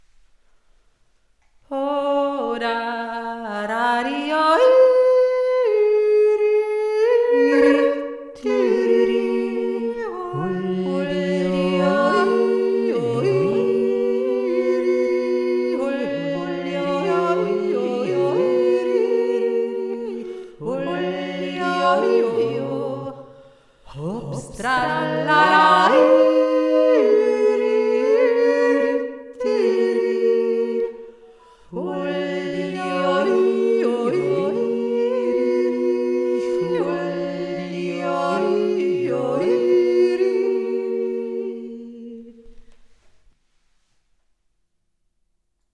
Dem WIGG seinen dreistimmig (anklicken)
Ich jodle ein und dann beginnt die Hauptstimme: Wir hören gut aufeinand - erkennen die Harmonien !!!